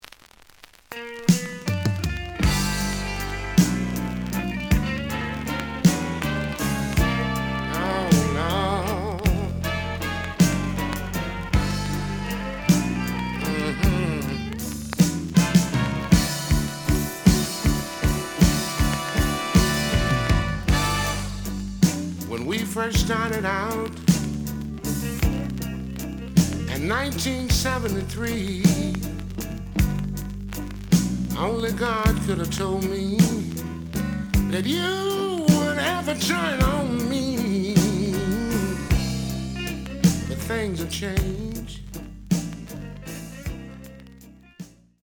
●Format: 7 inch
●Genre: Soul, 80's / 90's Soul